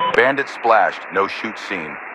Radio-pilotKillAir8.ogg